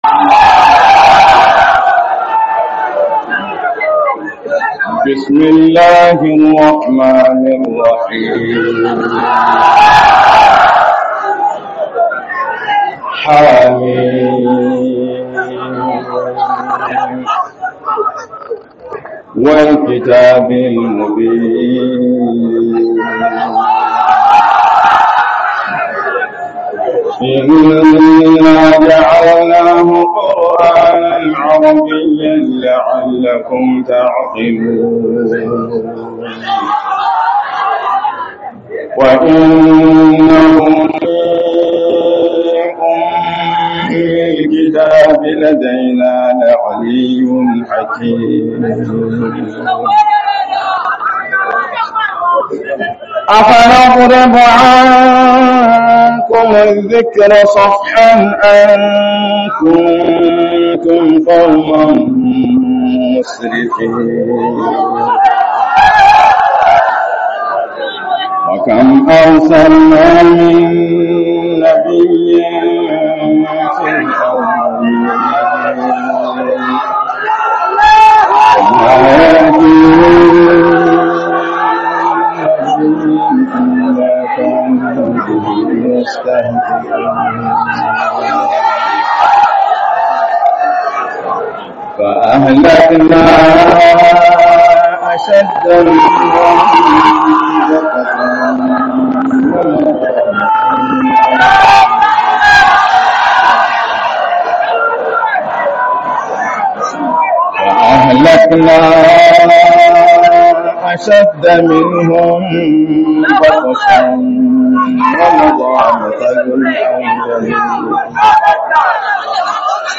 Wa'azin Kasa Kaduna 2025